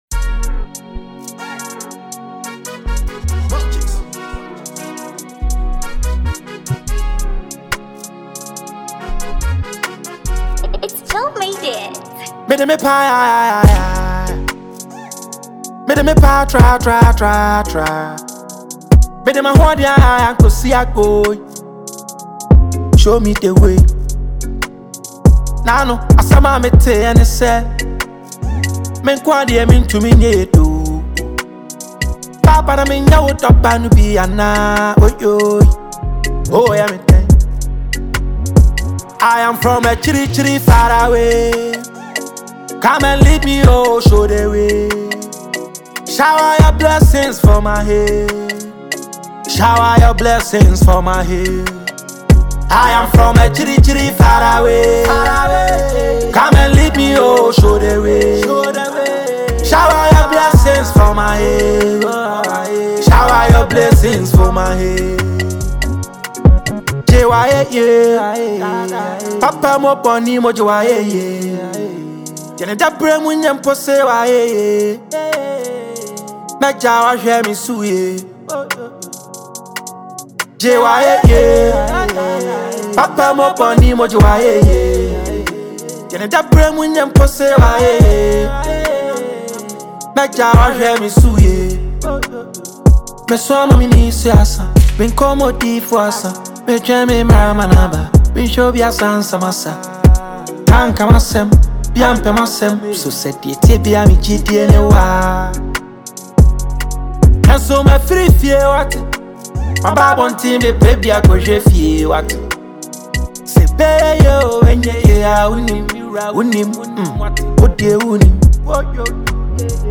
an award-winning Ghanaian rapper